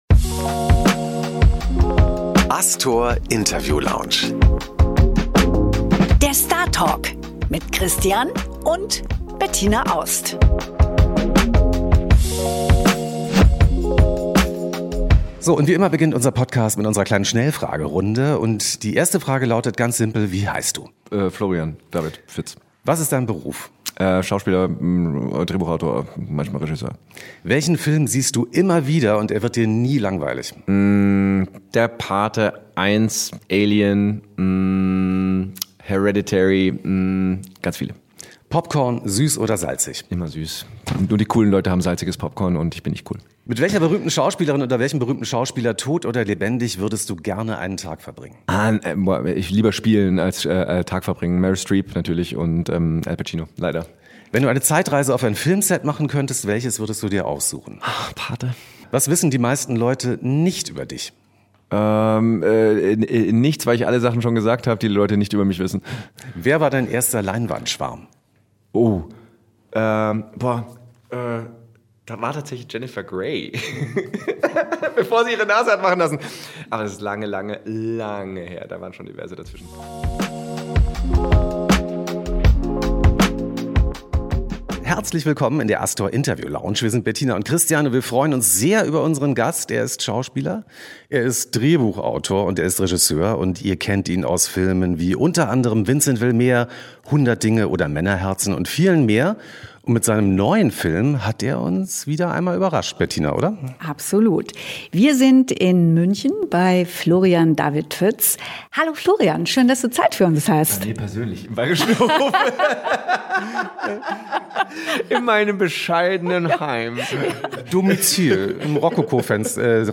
Diesmal zu Gast im Podcast: Florian David Fitz! In seinem neuen Film „No Hit Wonder“ spielt er einen gescheiterten Popstar. Wir sprechen mit Florian über Musik, Glück, Scheitern und Erfolg. Und dann singen wir auch noch zusammen.